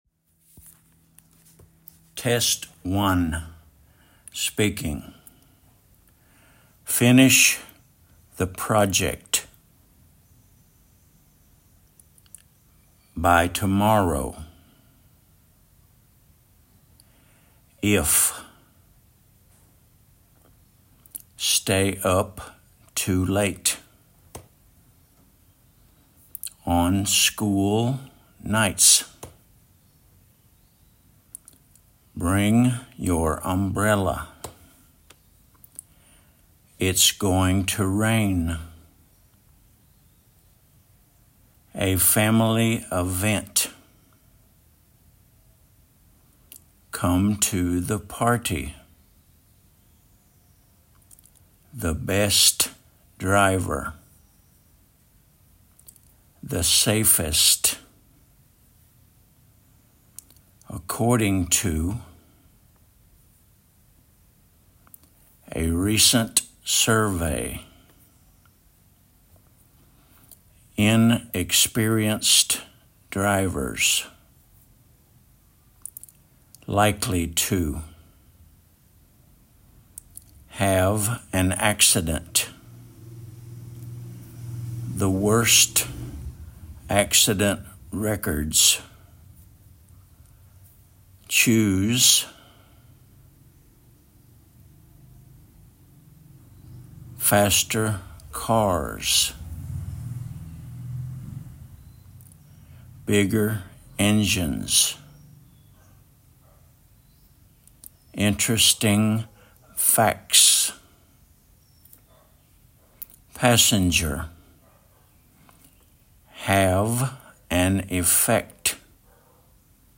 finish the project /ˈfɪnɪʃ ðə ˈprɒdʒɛkt/
the best driver /ðə bɛst ˈdraɪvə/
inexperienced drivers /ˌɪnɪkˈspɛrɪənst/